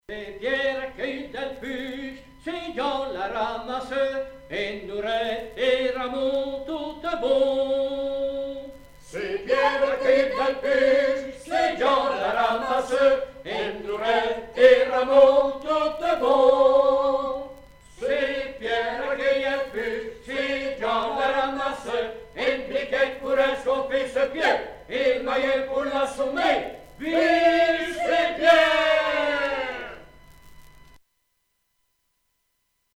Chant de quête
Pièce musicale éditée